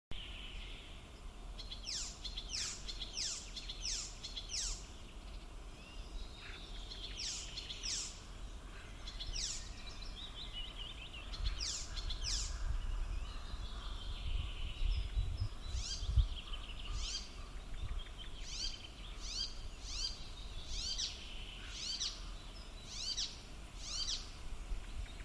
пересмешка, Hippolais icterina
Ziņotāja saglabāts vietas nosaukumsDaļēji izcirsts mežs
СтатусПоёт